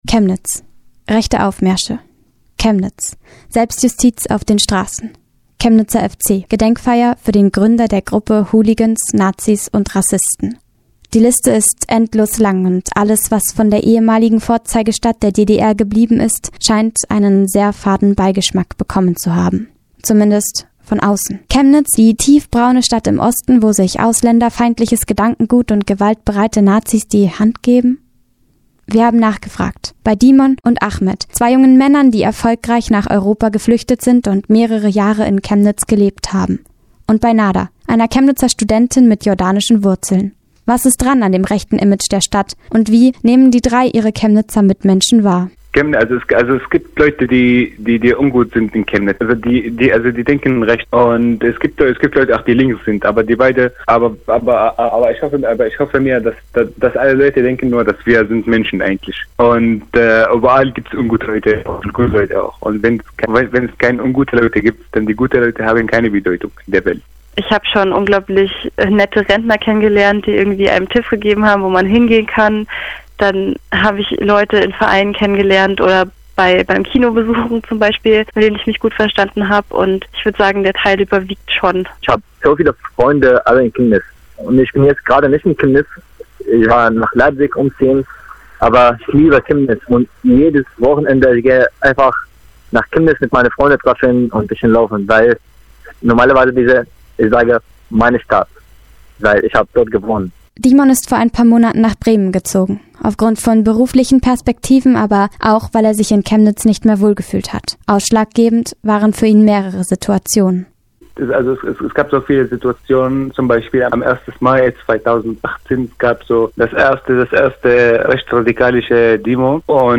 Sie wollte ein positiveres Bild der Stadt zeichnen, im Zuge dessen hat sie Zugezogene und Geflüchtete interviewt.